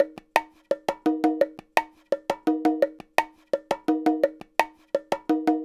Bongo 08.wav